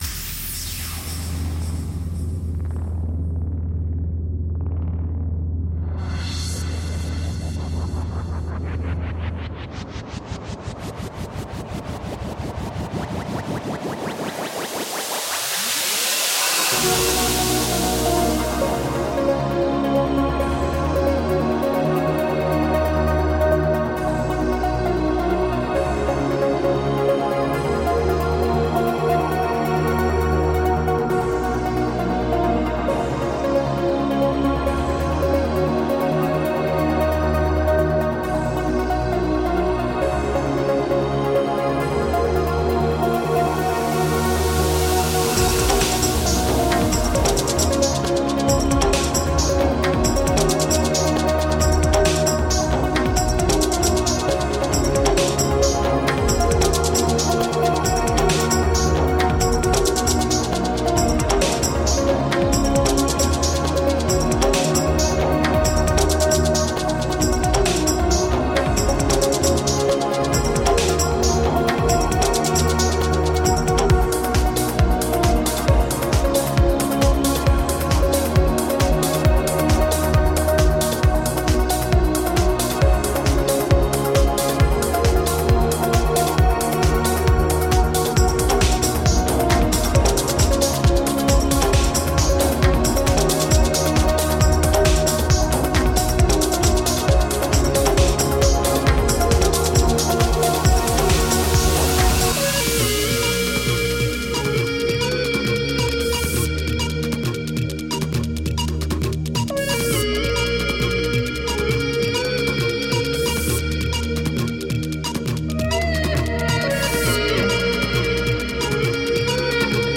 Electronica with a heart.